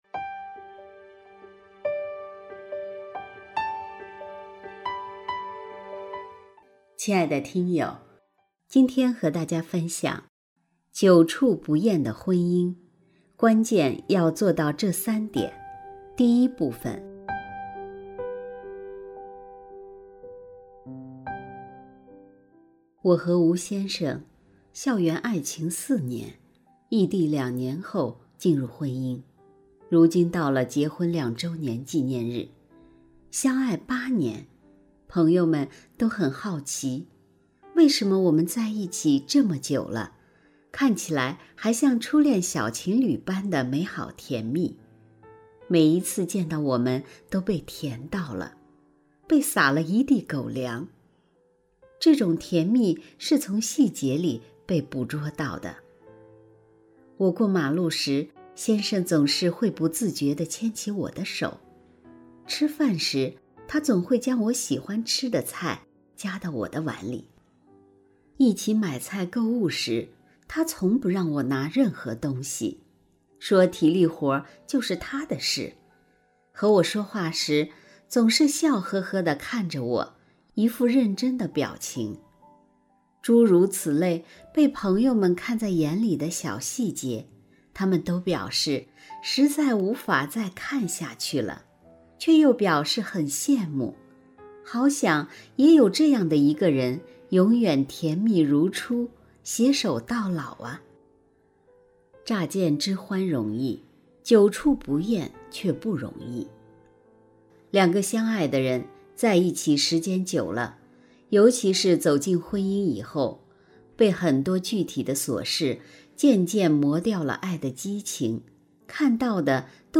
首页 > 有声书 > 婚姻家庭 > 单篇集锦 | 婚姻家庭 | 有声书 > 久处不厌的婚姻，关键要做到这三点（一）